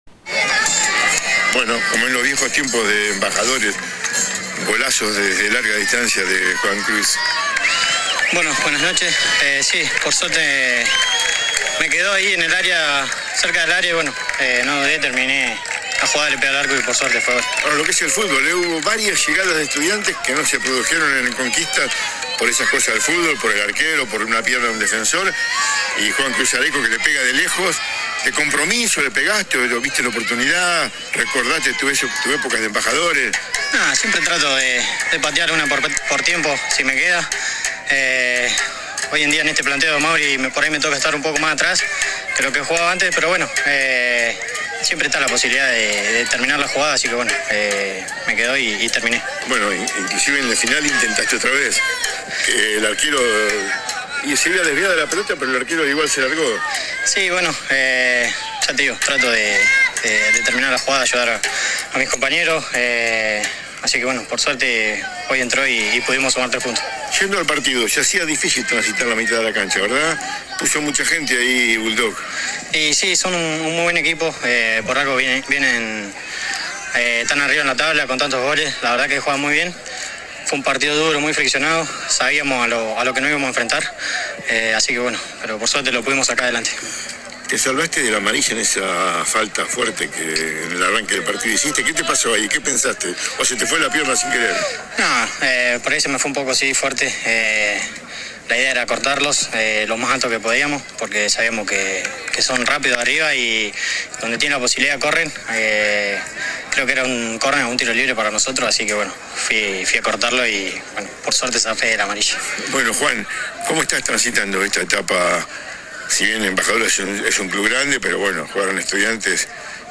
AUDIOS DE LAS ENTREVISTAS